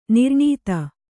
♪ nirṇīkta